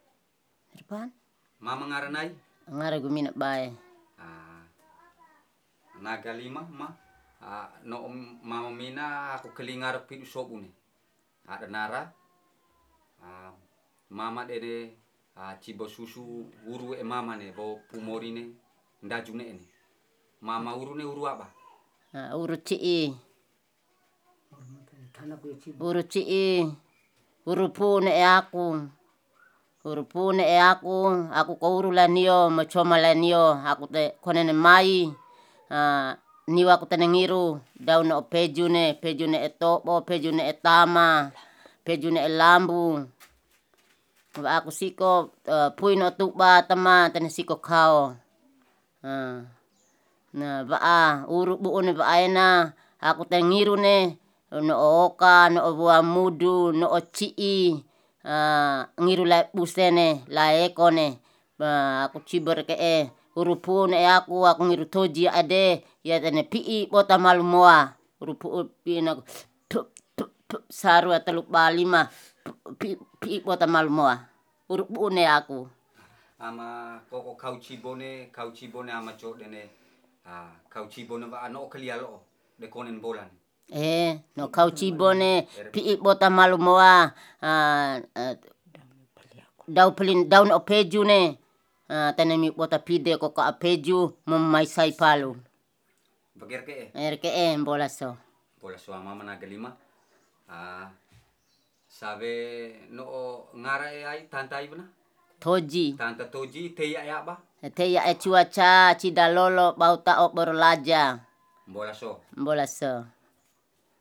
Genre: Medicine/huru.
Palu'e, Flores, Nusa Tenggara Timur, Indonesia. Recording made in kampong Cu'a, Cu'a domain.